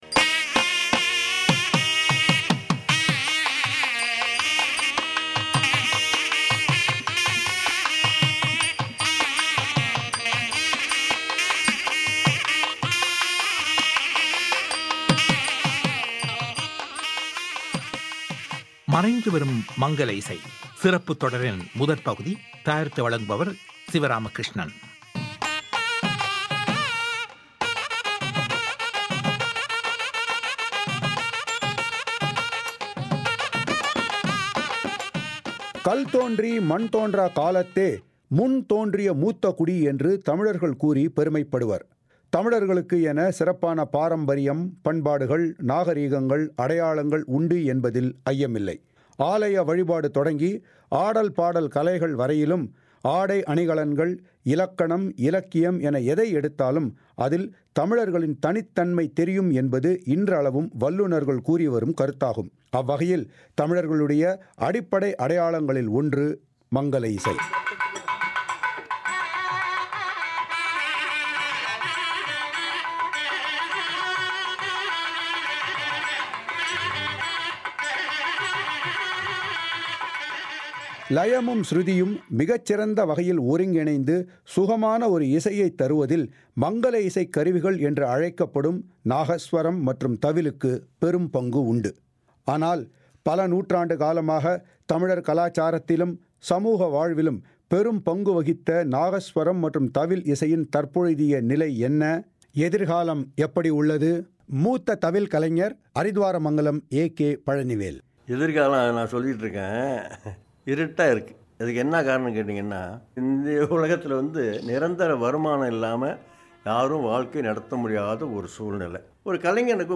இத்தொடரின் முதல் பகுதியில் இந்தக் கலையின் எதிர்காலம், மற்றும் வாத்தியத் தயாரிப்பாளர்கள், இசை ஆர்வலர்கள் ஆகியோரில் சில கருத்துக்களைக் கேட்கலாம்.